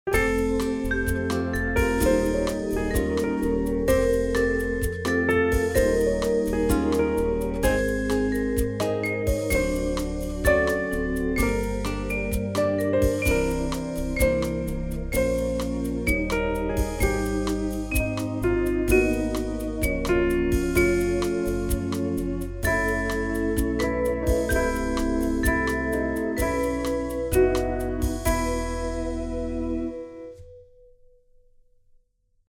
Plays verse of the track